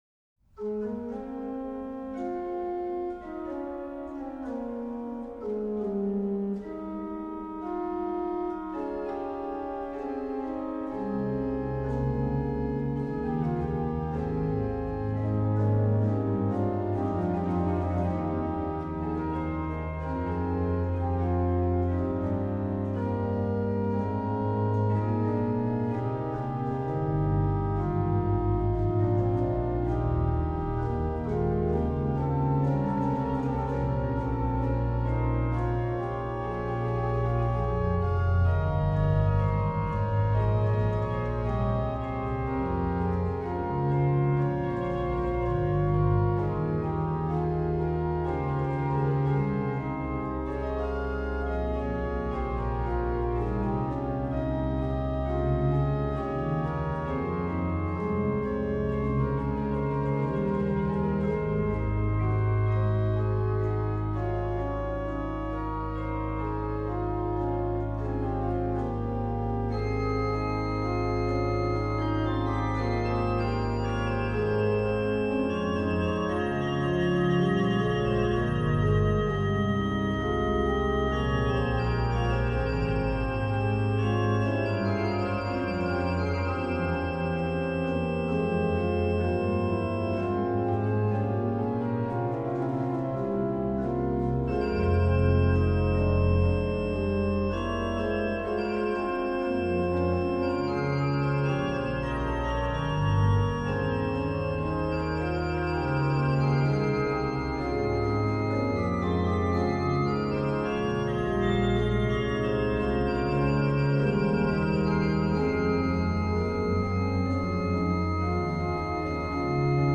Registration   rh: OW: Ged8, Rfl4, Nas3, 1 3/5
lh: HW: Pr8
Ped: Pr16, Oct8